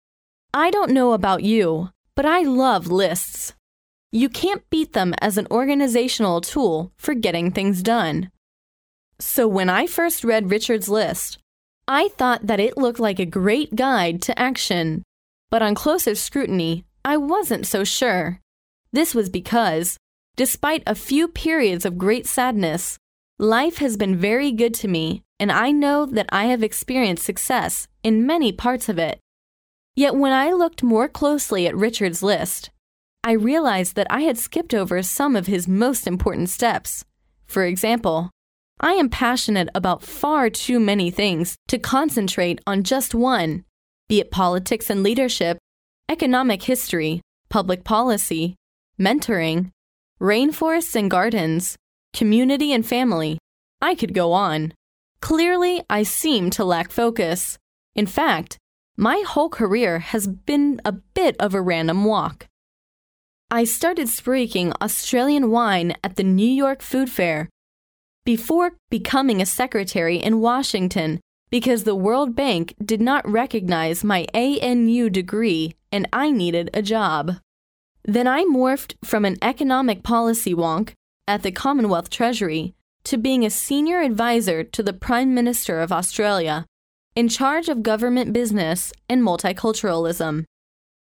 名人励志英语演讲 第85期:为成功做好准备(4) 听力文件下载—在线英语听力室